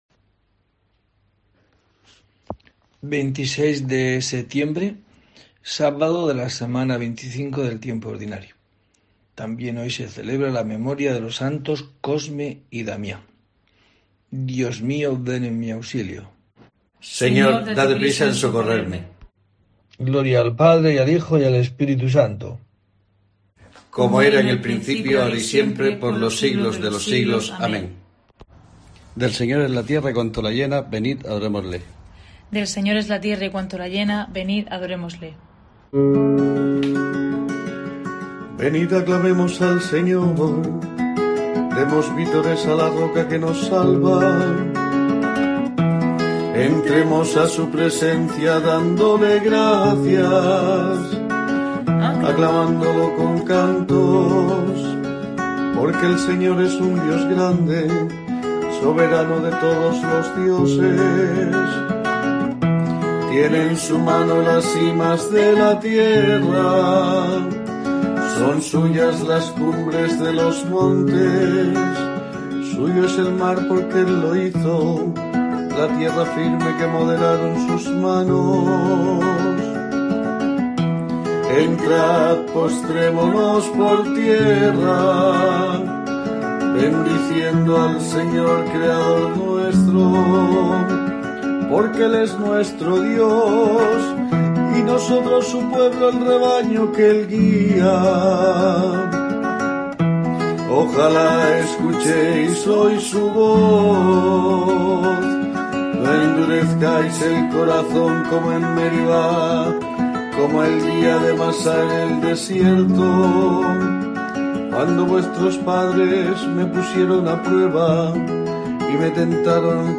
26 de septiembre: COPE te trae el rezo diario de los Laudes para acompañarte